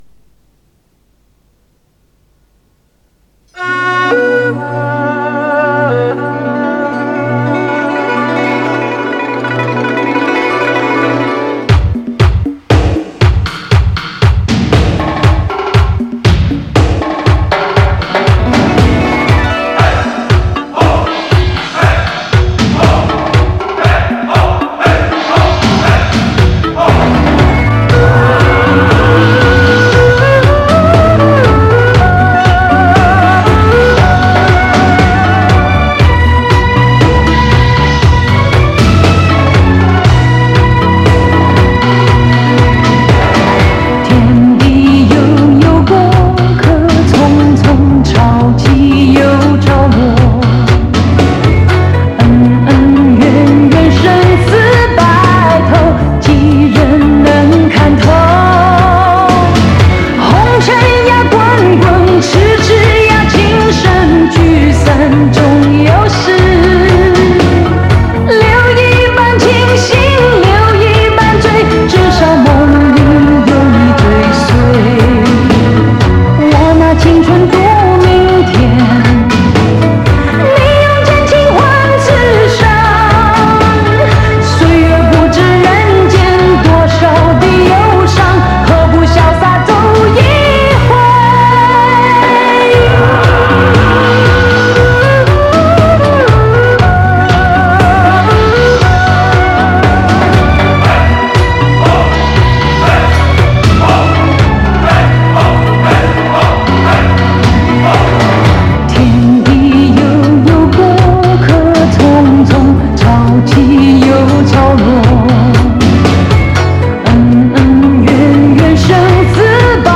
原版正宗
磁带数字化：2022-12-03